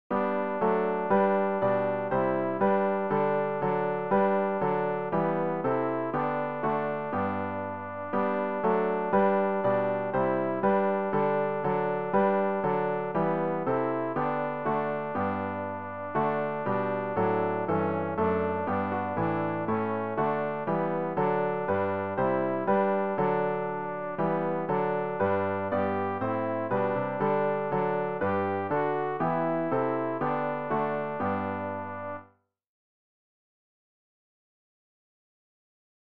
rg-793-herz-und-herz-alt.mp3